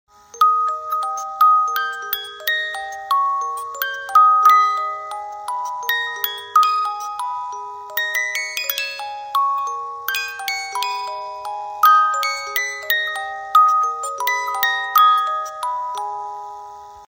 Romántico